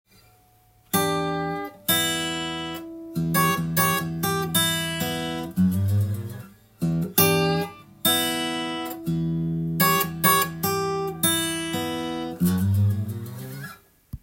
リズムに気をつけない場合
リズムに気をつけず　ラフに弾いてみました。
なんとなく曲にはなっていますが、
リズムを一定にしていないので、何かが欠けている状態になっています。